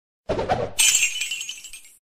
Download Diablo Gold Drop sound effect for free.